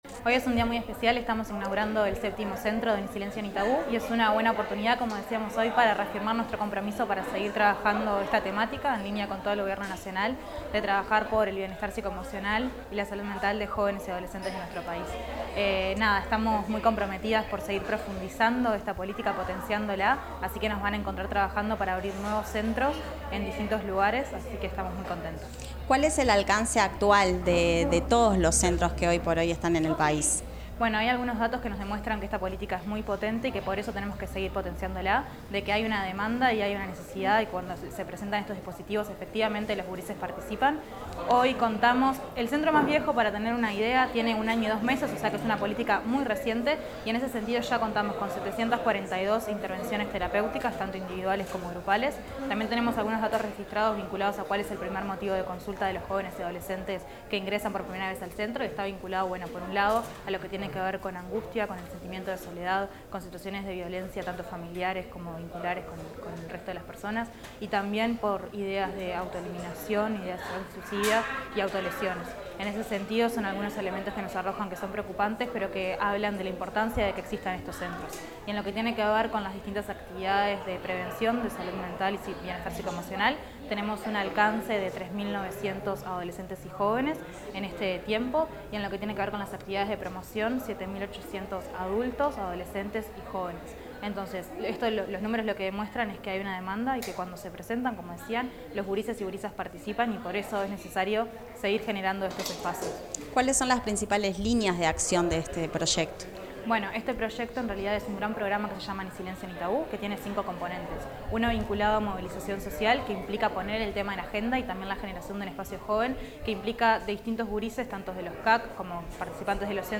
Declaraciones de la directora del INJU, Eugenia Godoy
Declaraciones de la directora del INJU, Eugenia Godoy 02/10/2025 Compartir Facebook X Copiar enlace WhatsApp LinkedIn Al finalizar la inauguración de un centro Ni Silencio Ni Tabú, la directora del Instituto Nacional de la Juventud (INJU), Eugenia Godoy, brindó declaraciones sobre las características de estos espacios y la política desplegada en ellos.